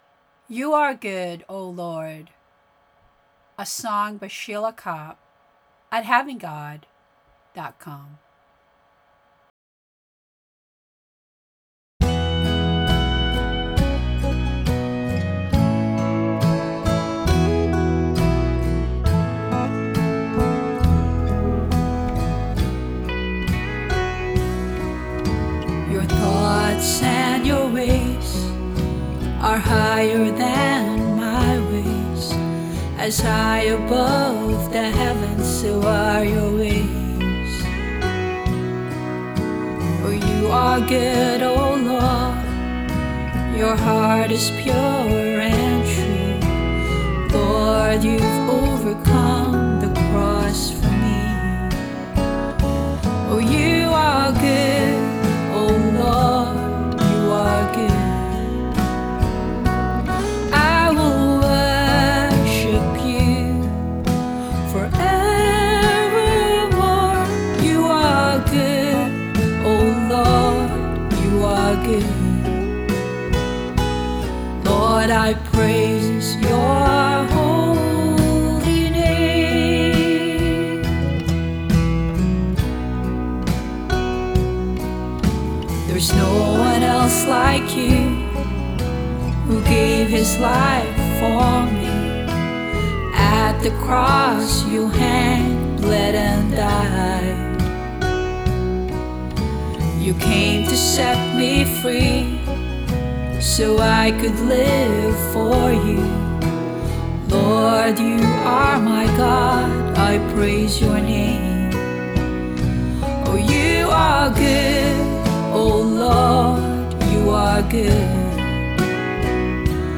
Instrumentation performed by Band-In-A-Box.